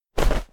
dive_roll_2.ogg